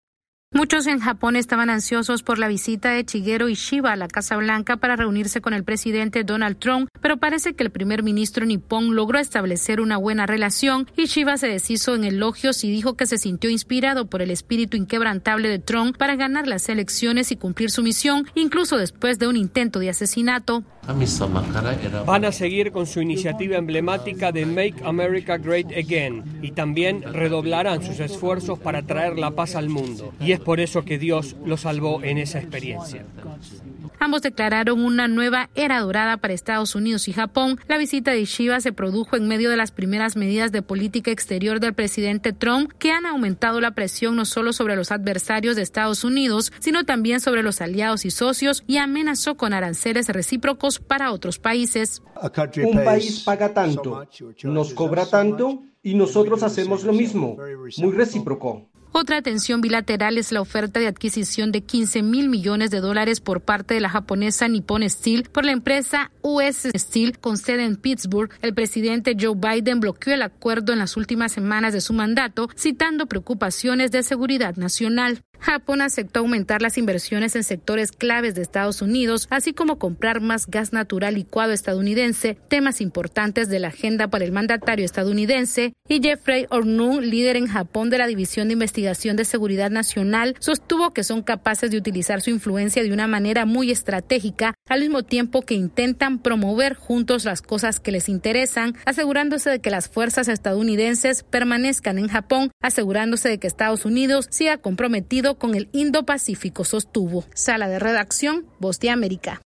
El presidente Donald Trump y el primer ministro japonés Shigeru Ishiba, inician lo que denominaron como una “nueva era dorada” para las relaciones entre Estados Unidos y Japón. Esta es una actualización de nuestra Sala de Redacción.